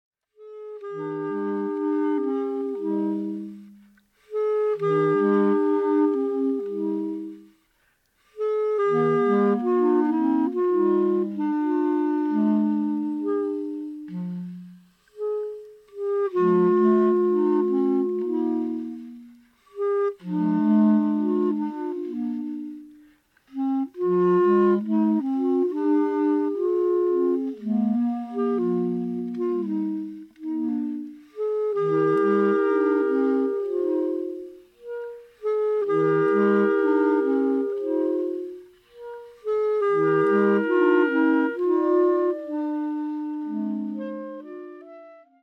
クラリネットだけでため息のような音楽を奏でる